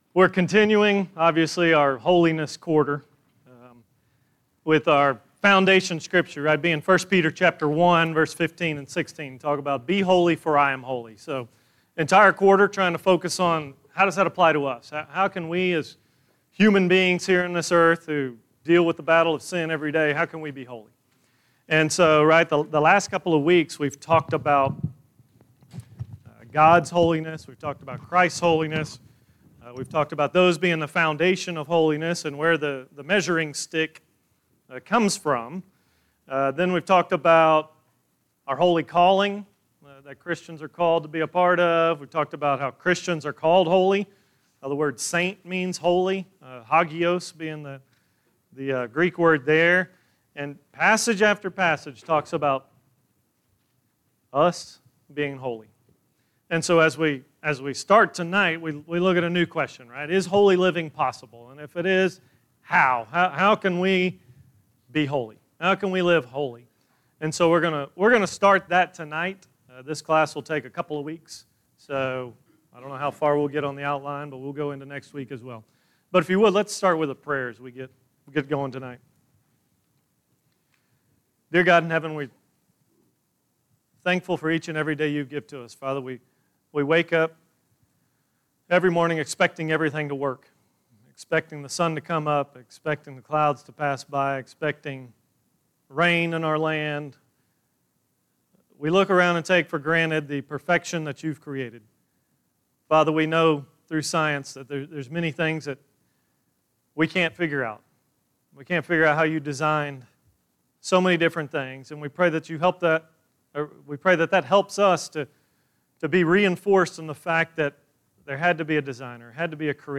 Just the gospel, plainly spoken. ScriptureStream compiles lessons presented at the Benchley church of Christ, located just north of Bryan, TX.